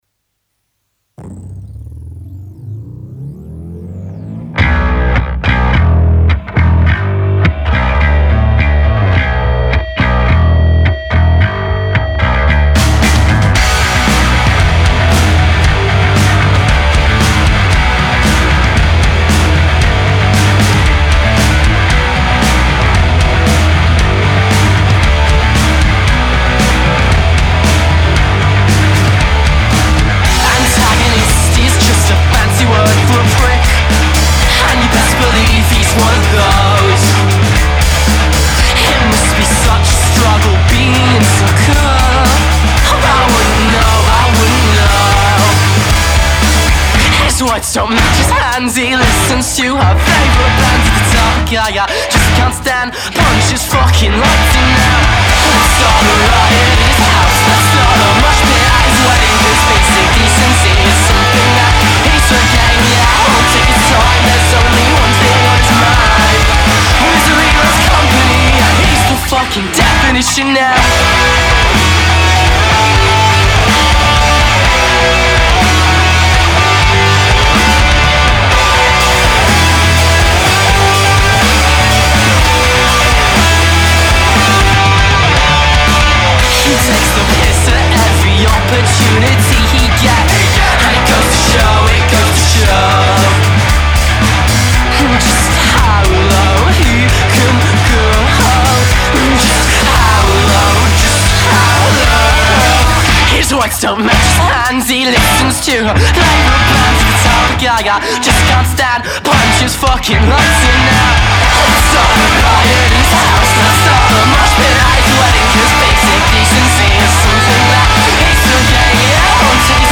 punk
kicks off with a self-assured grunge bassline
style guitars launch the listener into verse one